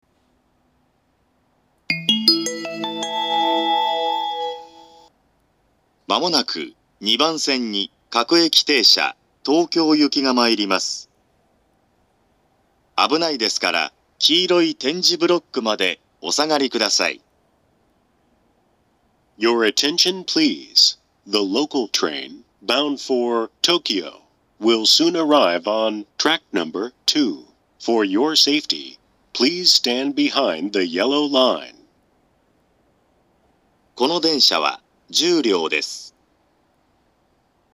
２番線接近放送
２番線到着放送
発車メロディー（ＪＲＥ-ＩＫＳＴ-０0１-0１）
１番線に比べると鳴りにくいですが、こちらも余韻までは鳴りやすいです。
shinkiba2bansen-sekkin3.mp3